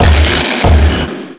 pokeemerald / sound / direct_sound_samples / cries / conkeldurr.aif
conkeldurr.aif